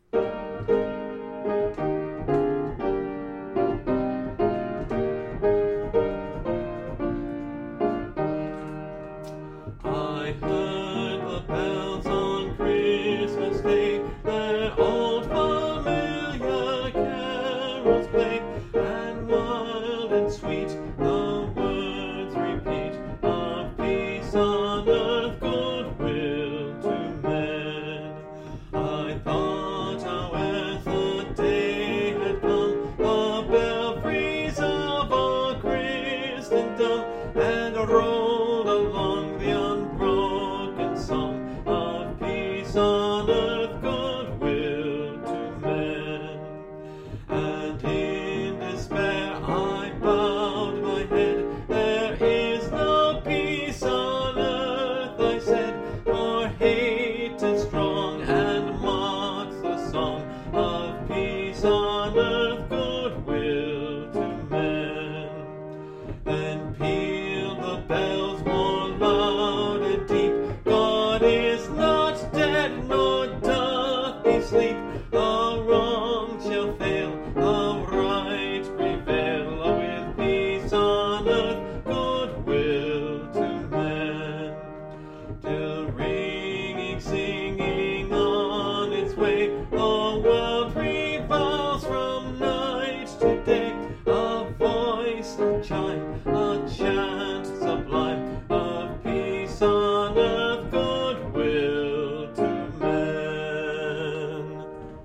(Part of a series singing through the hymnbook I grew up with: Great Hymns of the Faith)
This is one of my favorite carols, and of course I always have to do all 5 verses.